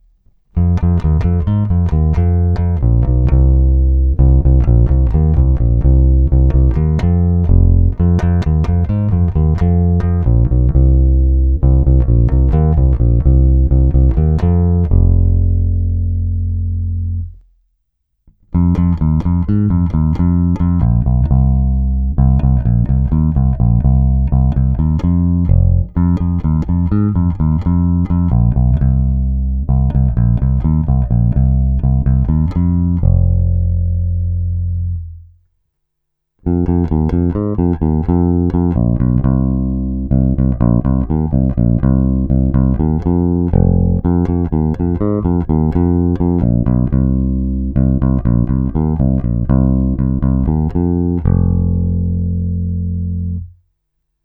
Hlazenky base sluší, zvuk je pěkně tučný, pevný, má ty správné středy tmelící kapelní zvuk.
Není-li uvedeno jinak, následující nahrávky jsou provedeny rovnou do zvukové karty, jen normalizovány, jinak ponechány bez úprav.
Snímač u kobylky